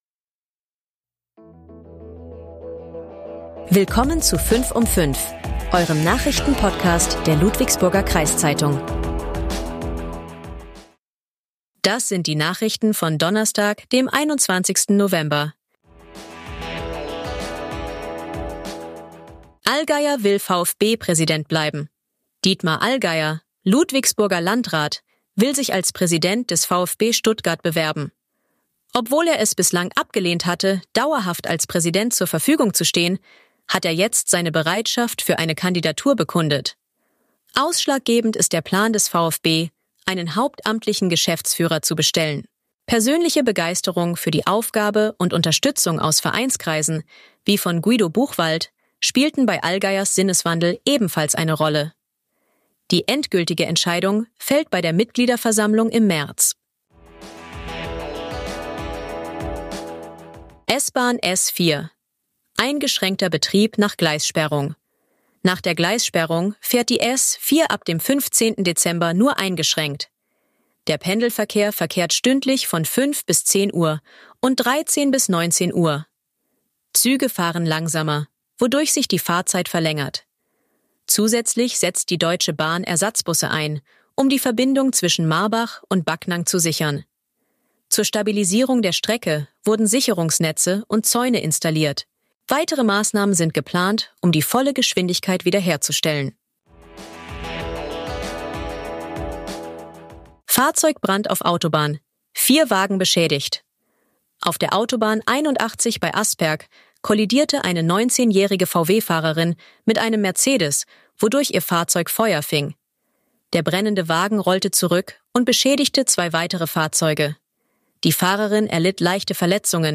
Nachrichten , Gesellschaft & Kultur